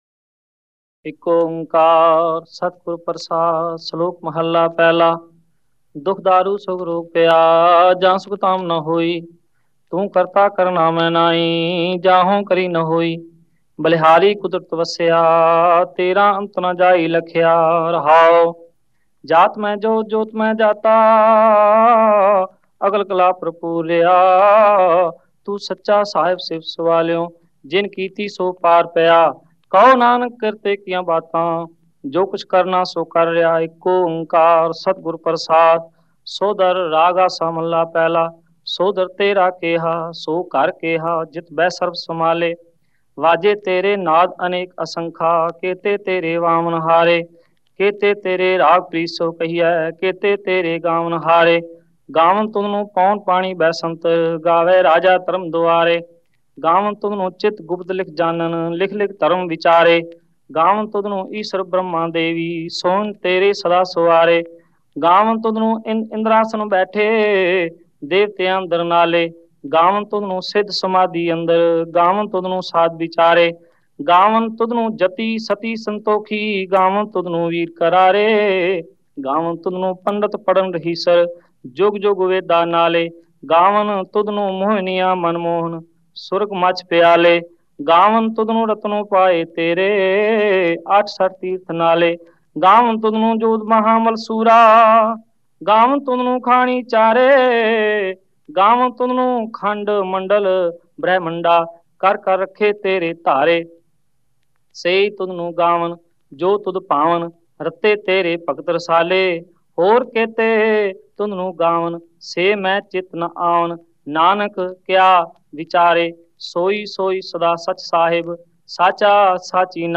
Gurbani Ucharan(Paath Sahib)
Album:Rehras Sahib Genre: -Gurbani Ucharan